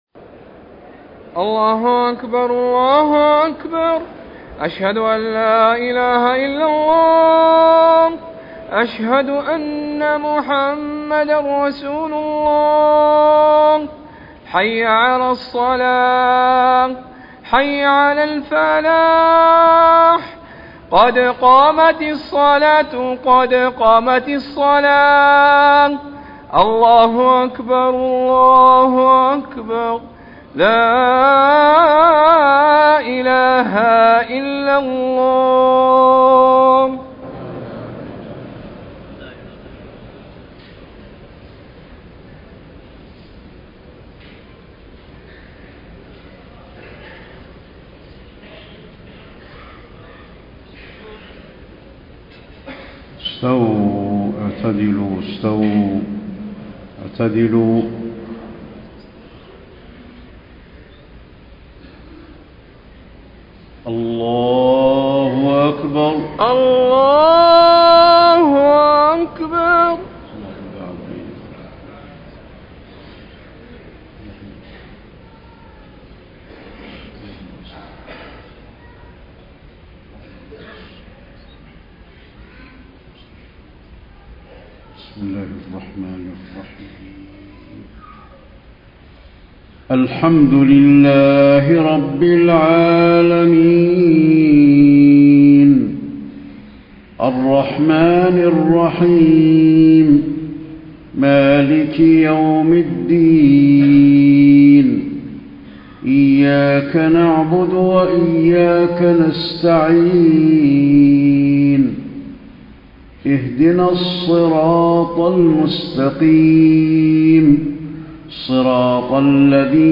صلاة العشاء 4 - 4 - 1434هـ من سورة الأحزاب > 1434 🕌 > الفروض - تلاوات الحرمين